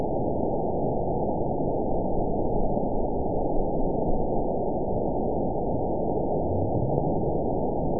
event 920534 date 03/28/24 time 22:10:43 GMT (1 year, 1 month ago) score 9.64 location TSS-AB03 detected by nrw target species NRW annotations +NRW Spectrogram: Frequency (kHz) vs. Time (s) audio not available .wav